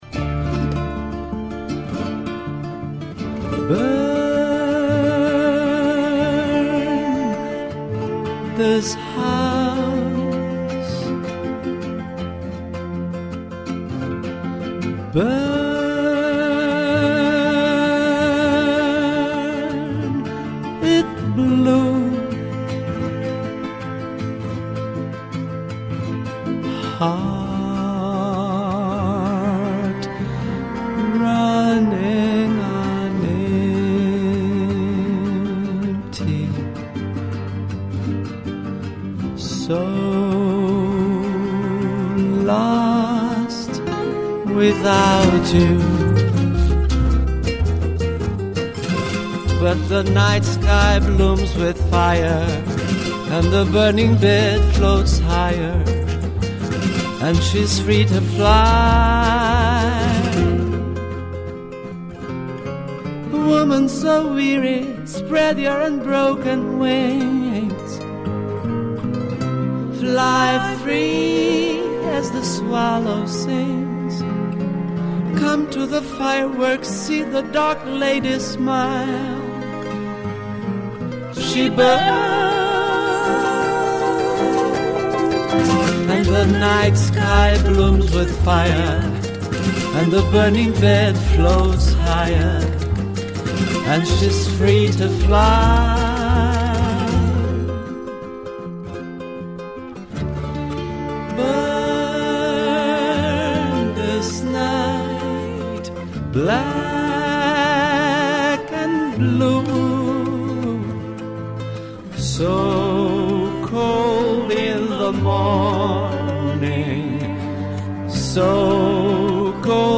песня.mp3